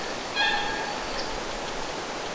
laulujoutsenen ääni
laulujoutsenen_aani.mp3